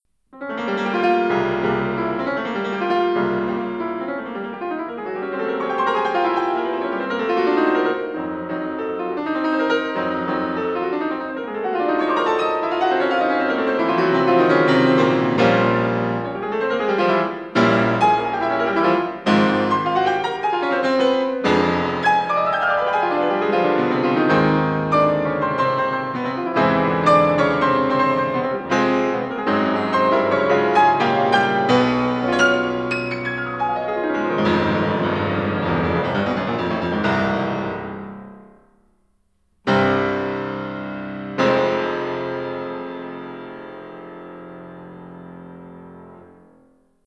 The analyzed Chopin performances were audio recordings played by 5 famous pianists:
Martha Argerich recorded in 1997